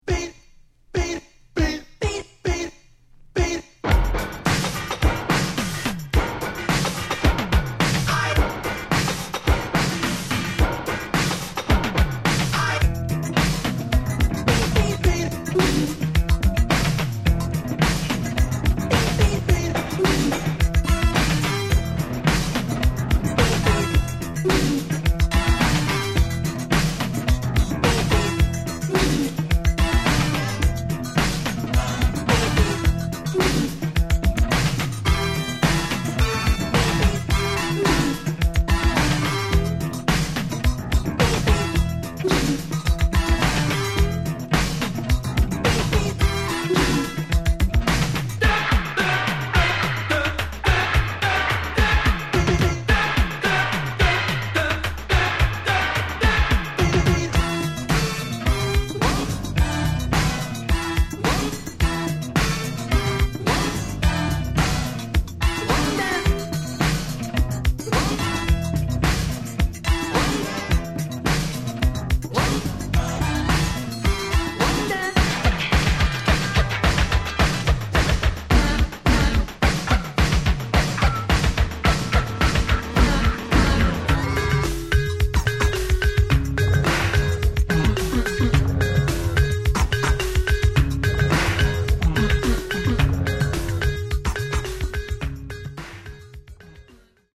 Genre: Funk/Hip-Hop/Go-Go